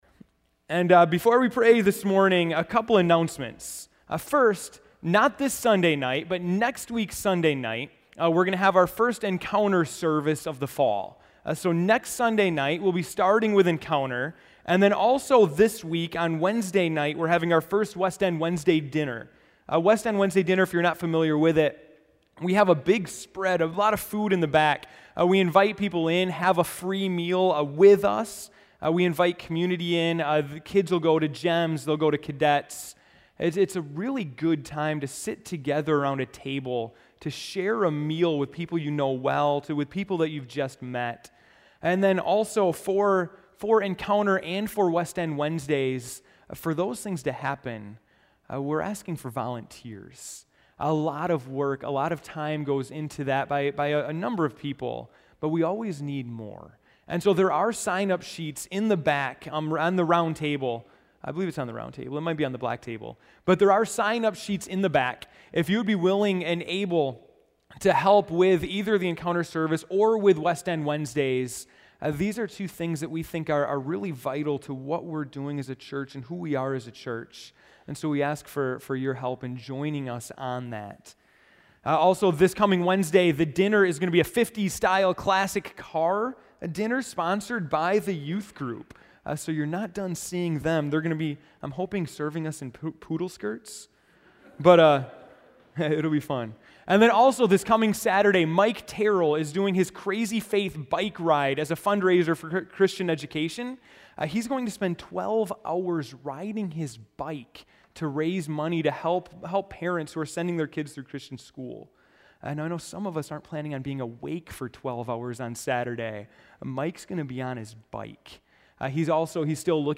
September 15, 2013 (Morning Worship)